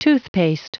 Prononciation du mot toothpaste en anglais (fichier audio)
Prononciation du mot : toothpaste
toothpaste.wav